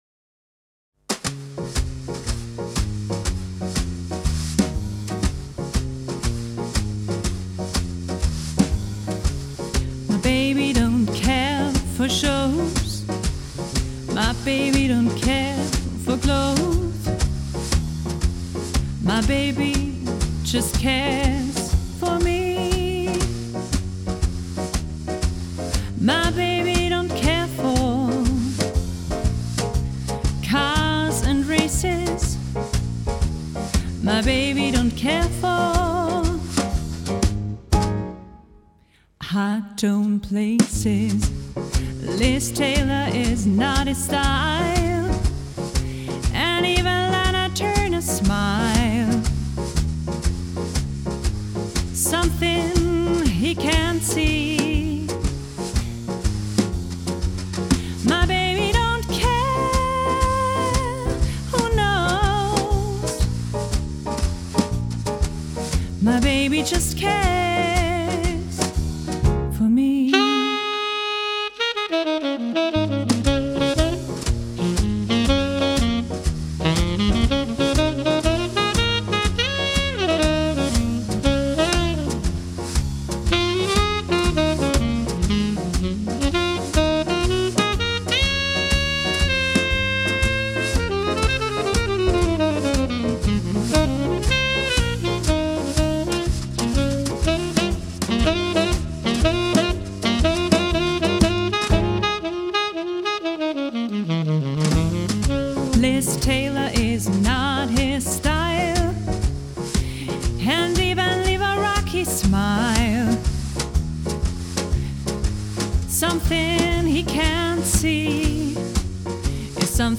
Quintett